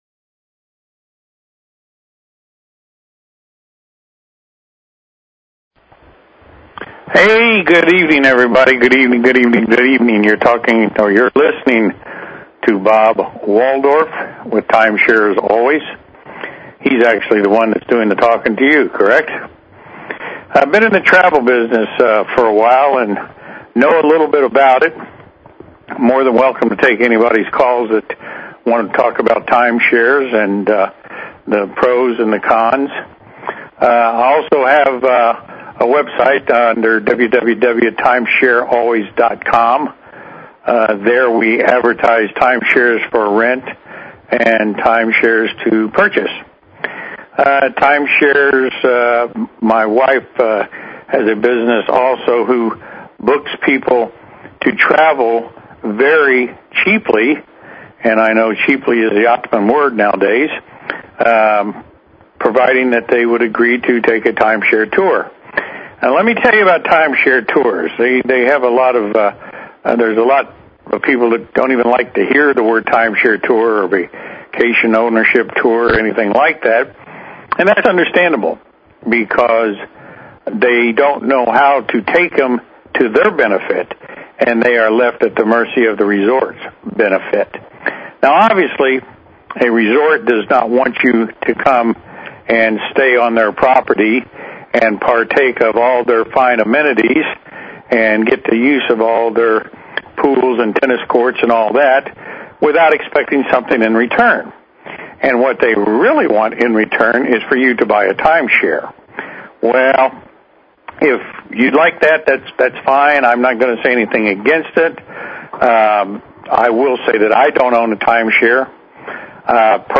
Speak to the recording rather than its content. Call in to the program anytime and let's talk - Timeshares.